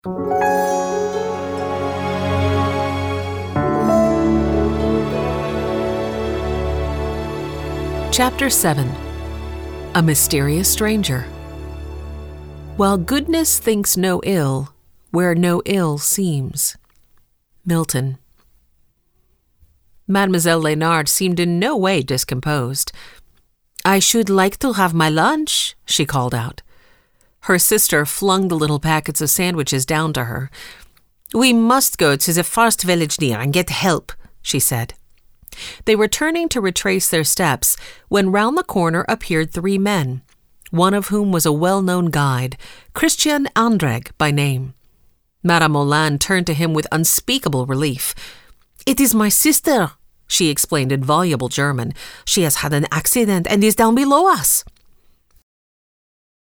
Audiobook: Chateau By the Lake - MP3 download - Lamplighter Ministries
Chateau-by-the-Lake-Audiobook-Sample-1.mp3